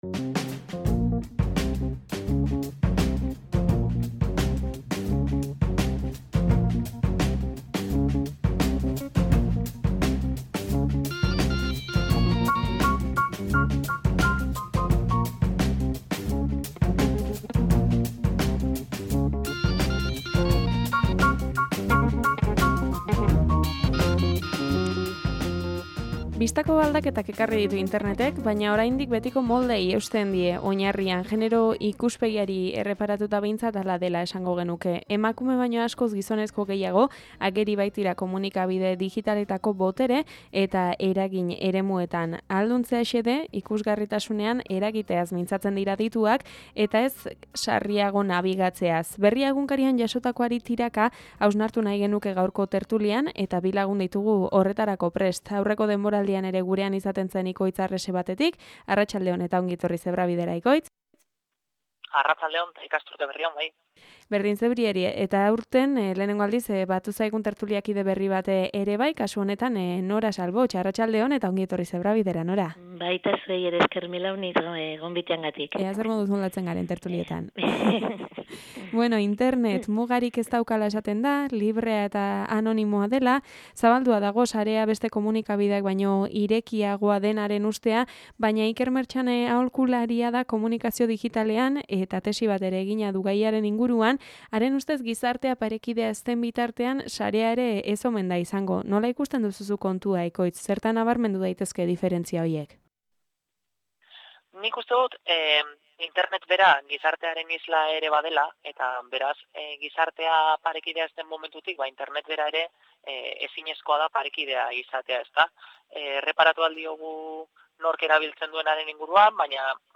TERTULIA: Internet, emakumeen ikusgarritasunerako plaza edo gizarteko bereizketaren ispilu?